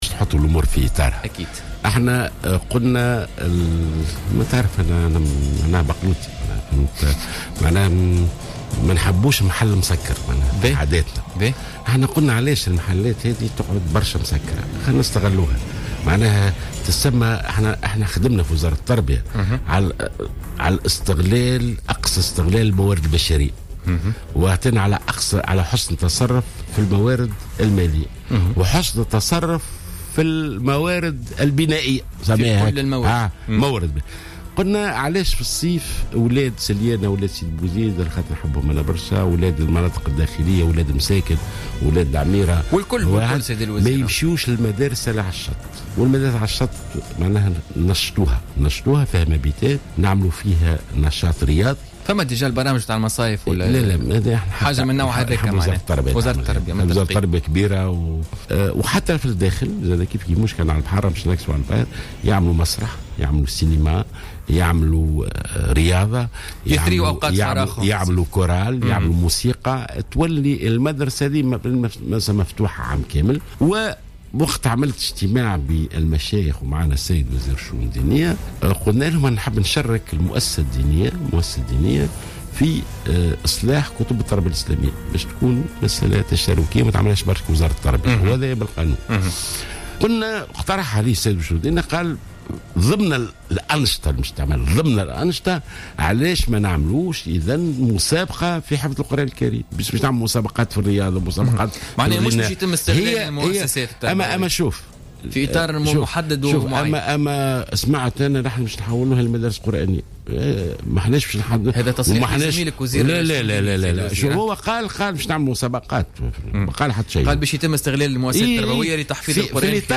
قال وزير التربية ناجي جلول في تصريح للجوهرة أف أم في برنامج بوليتكا لليوم الجمعة 22 أفريل 2016 إن المدارس والمعاهد التي ستكون مفتوحة في العطل لن تقتصر على تحفيظ القرآن مثلما تم تداوله.